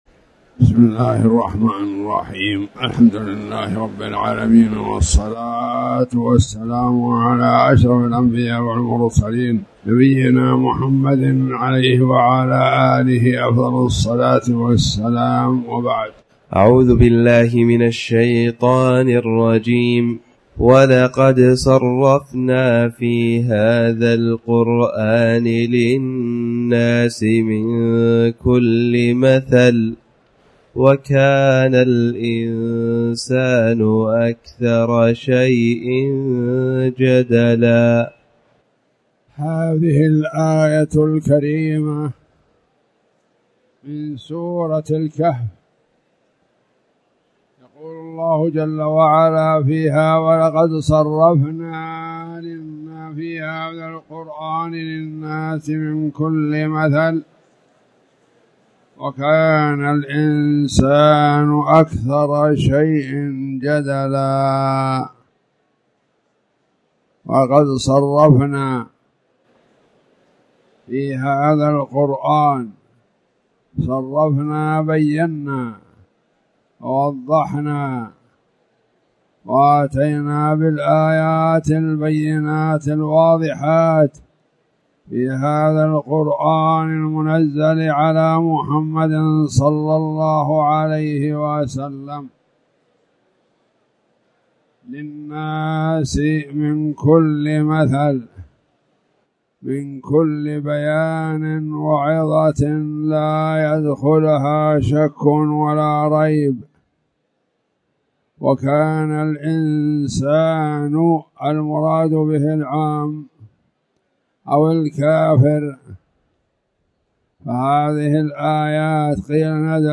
تاريخ النشر ١ ذو الحجة ١٤٣٨ هـ المكان: المسجد الحرام الشيخ